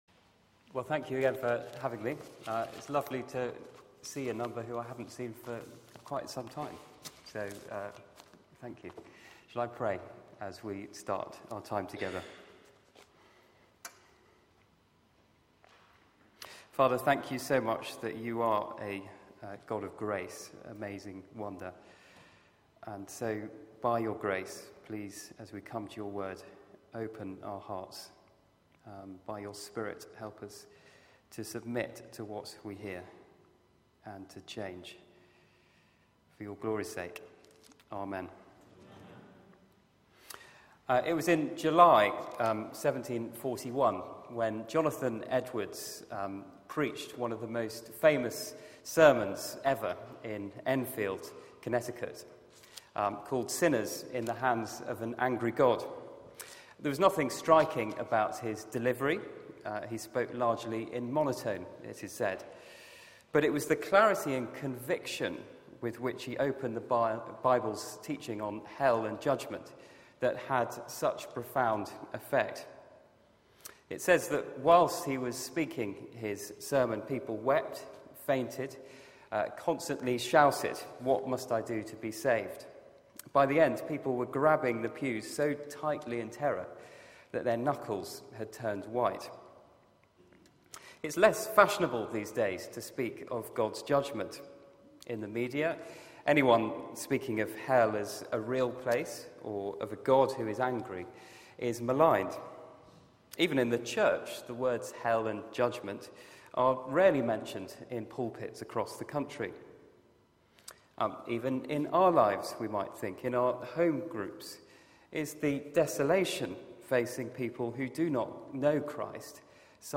Media for 4pm Service on Sun 23rd Mar 2014 16:00 Speaker
Sermon